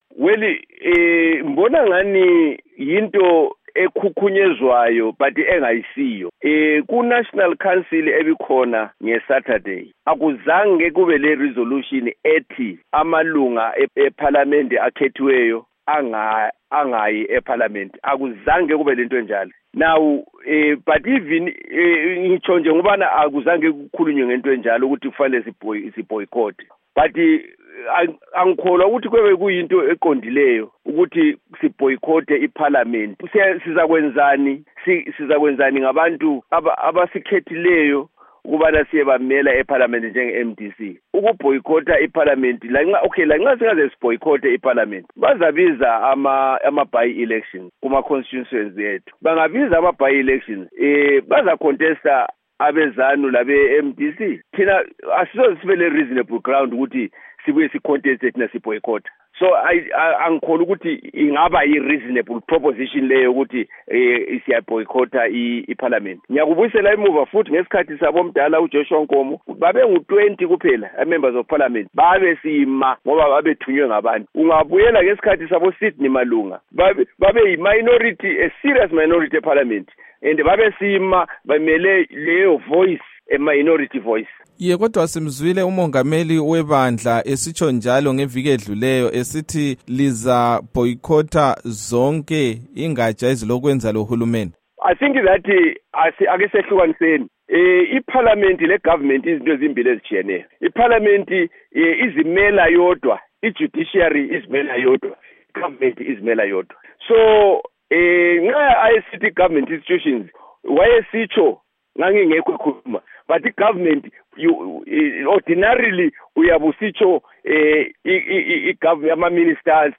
Ingxoxo loMnu. Sam Sipepa Nkomo